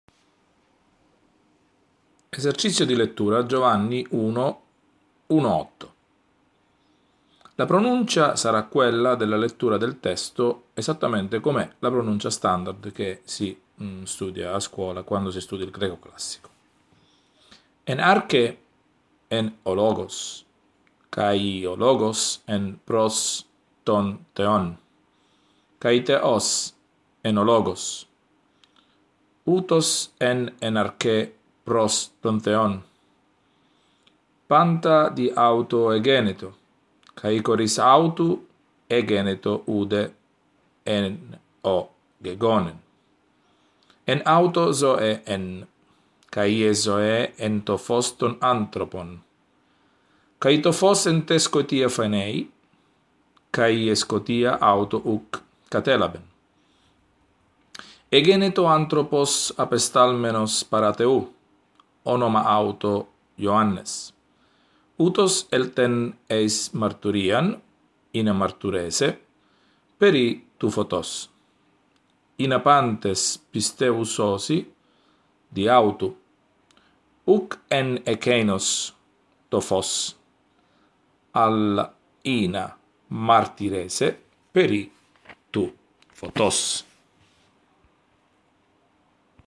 Audio lezione 2
lezione-2-greco-biblico.mp3